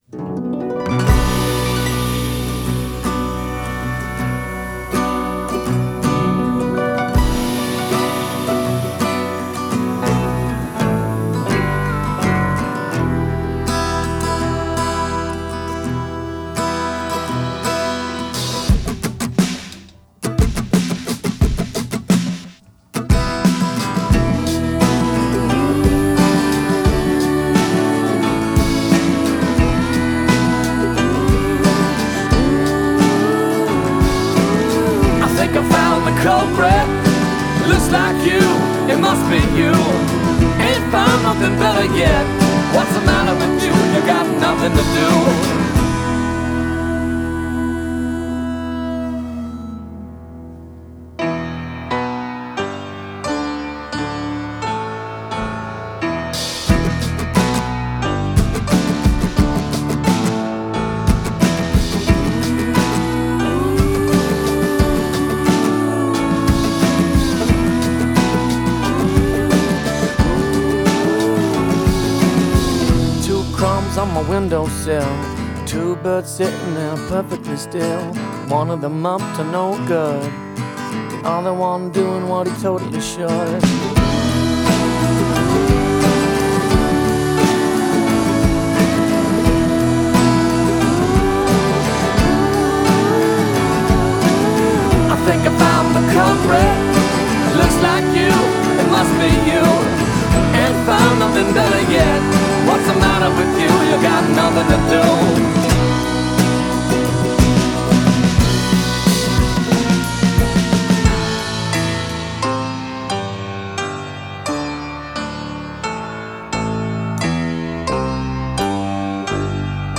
Genre: Blues Rock